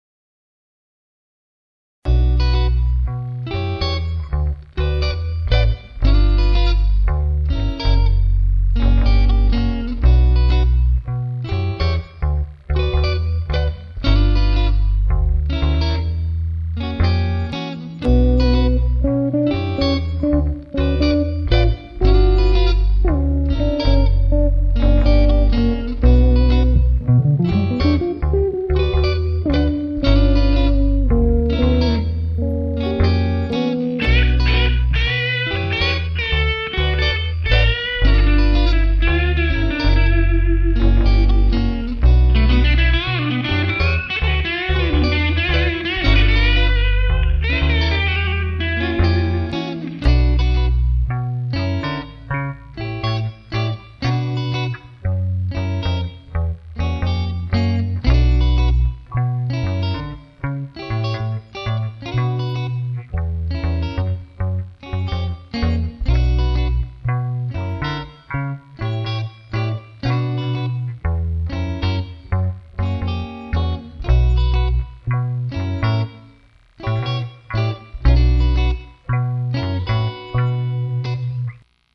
Le son du JMP1 en pédale.....!?
Le son Marshal dans une boite en fer : Shred Master.